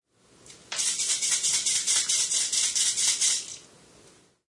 描述：Toilette cleaner spray in a Berlin Bathroom .Type of microphone: SE4 Stereo.File quality: .wav 96.000Hz 24bit
标签： spraybathroom spraysoap spraytoilette toilettecleaner cleanspray
声道立体声